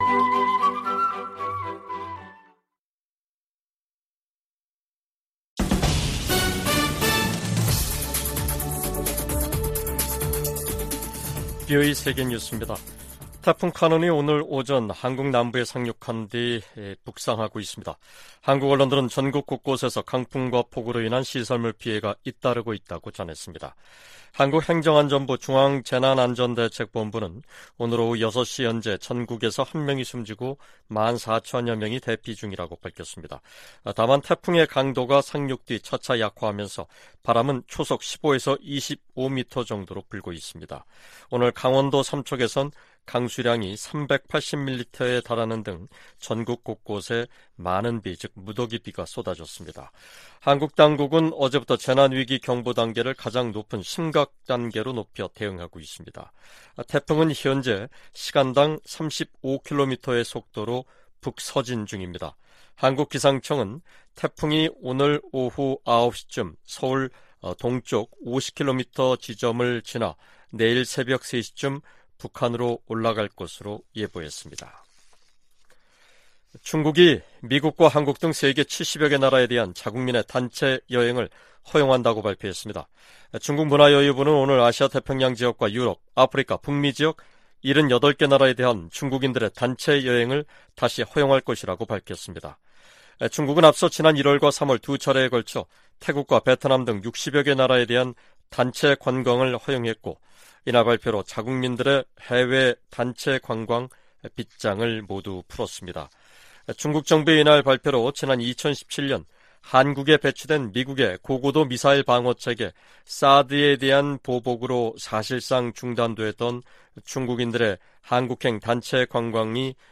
VOA 한국어 간판 뉴스 프로그램 '뉴스 투데이', 2023년 8월 10일 2부 방송입니다. 조 바이든 미국 대통령이 다음 주 미한일 정상회의에서 역사적인 논의를 고대하고 있다고 백악관 고위관리가 밝혔습니다. 미 국무부는 북한의 개성공단 무단 가동 정황과 관련해 기존 제재를 계속 이행할 것이라고 밝혔습니다. 김정은 북한 국무위원장이 '을지프리덤실드' 미한 연합연습을 앞두고 노동당 중앙군사위원회 확대회의를 열어 '공세적 전쟁 준비'를 강조했습니다.